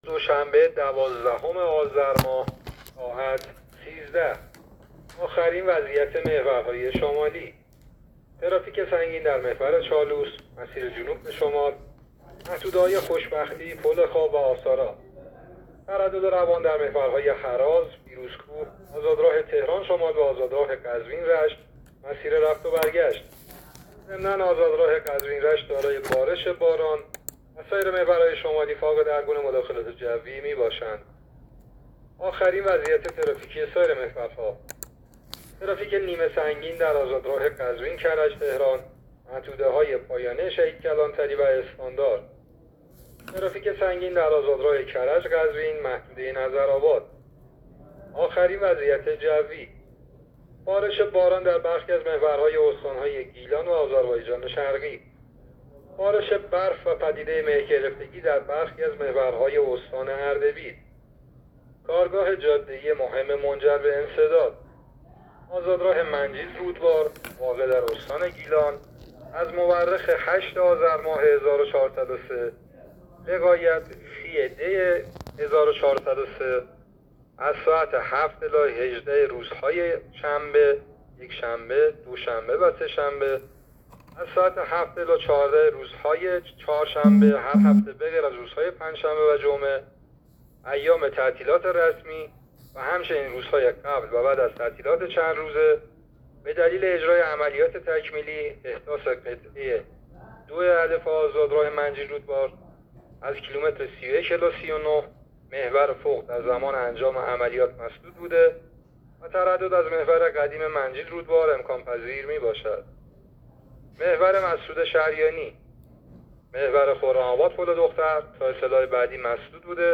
گزارش رادیو اینترنتی از آخرین وضعیت ترافیکی جاده‌ها تا ساعت ۱۳ دوازدهم آذر؛